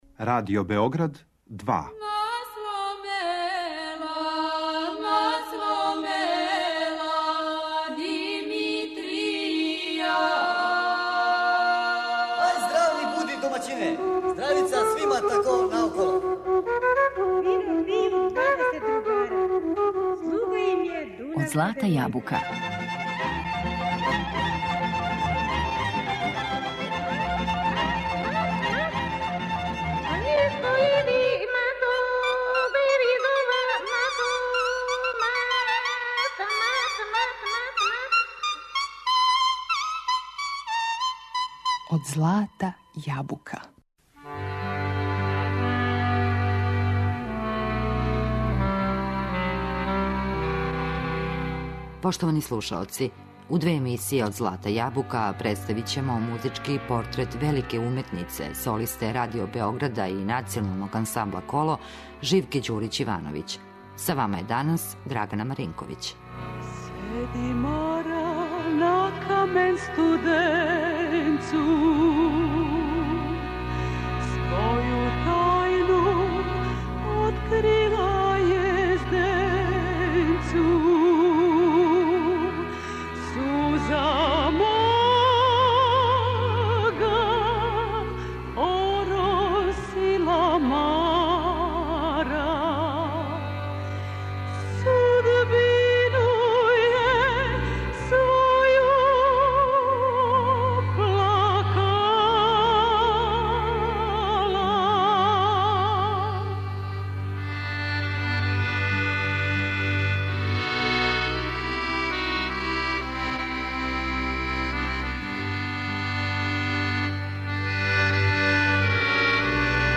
И данас, после толико година, на програмима Радио Београда слушаоци могу да уживају у њеном непоновљивом гласу. Забележила је и сачувала од заборава најлепше песме са ових простора.